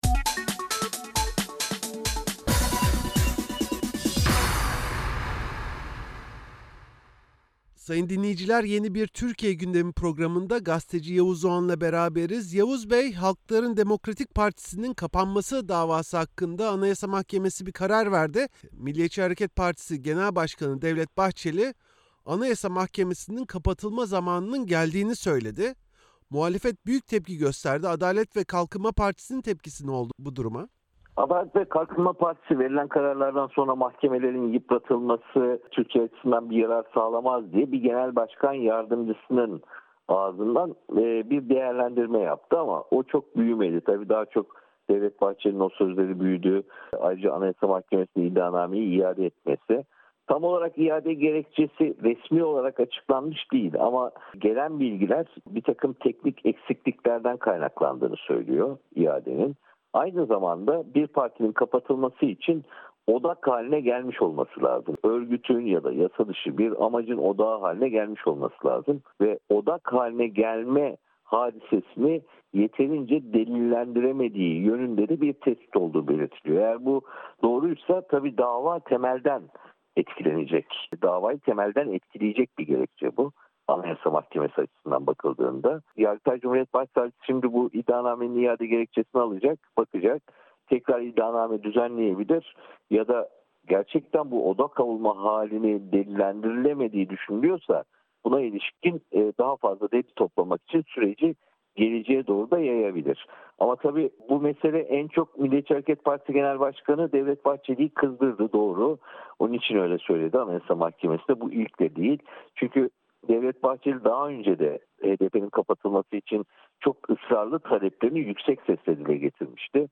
Gazeteci Yavuz Oğhan, Türkiye’de günlük COVID-19 vaka 40 bini geçerken hükümetin Ramazan’ın başlayacağı 13 Nisan’a kadar ilave bir önlem almayacağını açıklamasının tepki çektiğini söyledi.